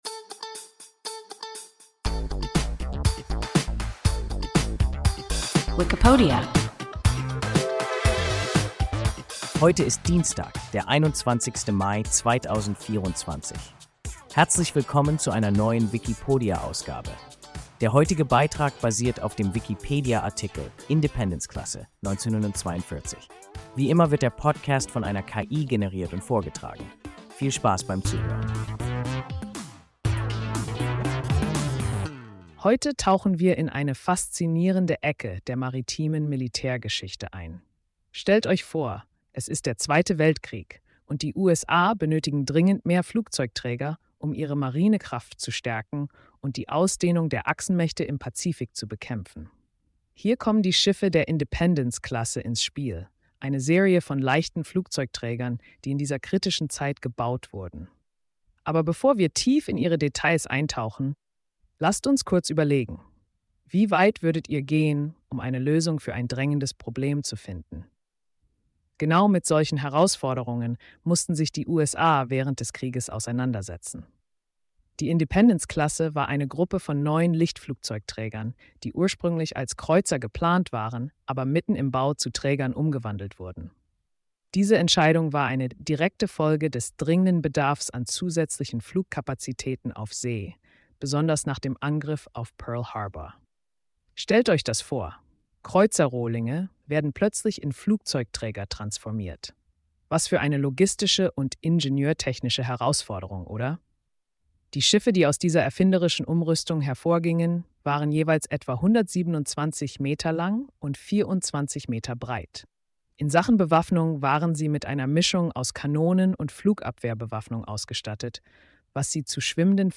Independence-Klasse (1942) – WIKIPODIA – ein KI Podcast